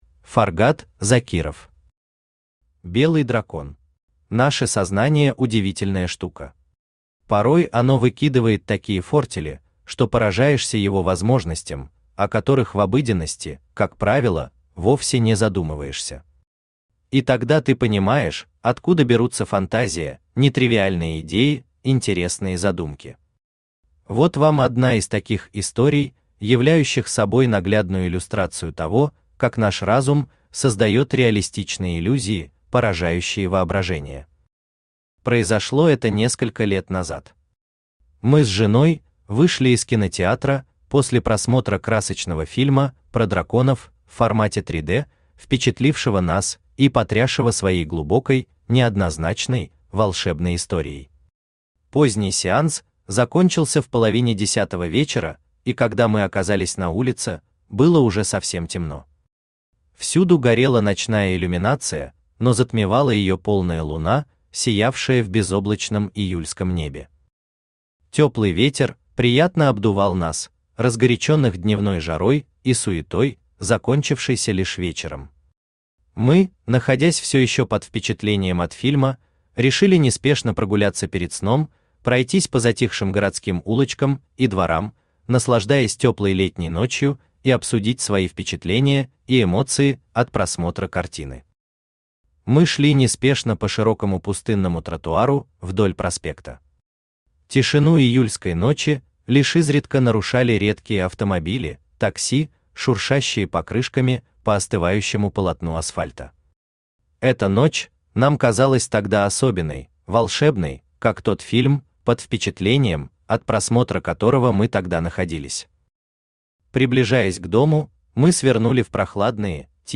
Aудиокнига Белый Дракон Автор Фаргат Закиров Читает аудиокнигу Авточтец ЛитРес.